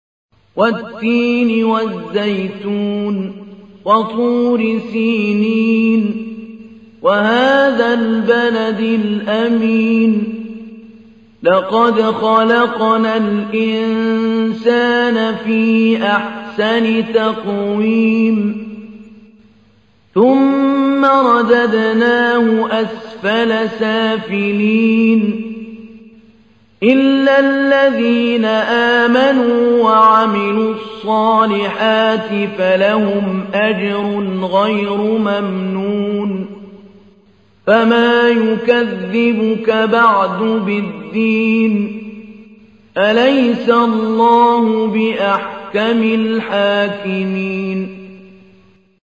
تحميل : 95. سورة التين / القارئ محمود علي البنا / القرآن الكريم / موقع يا حسين